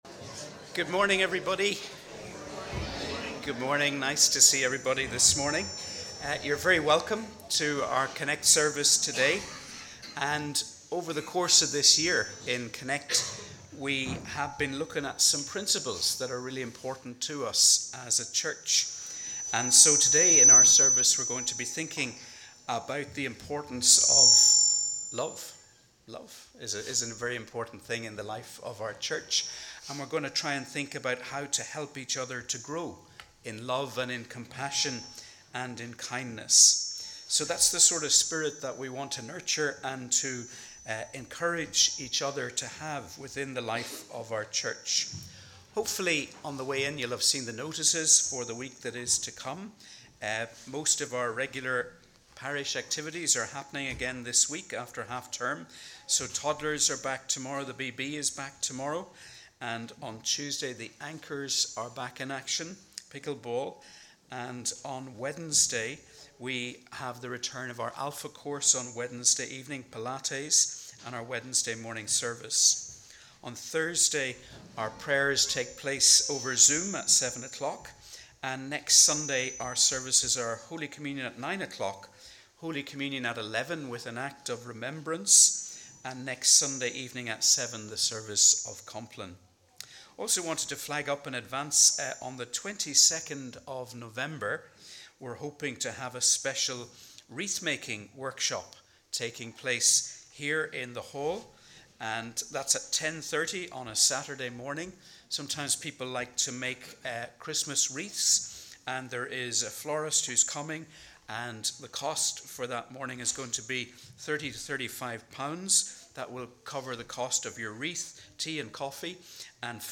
We warmly welcome you to our CONNEC+ service as we worship together on the 4th Sunday before Advent.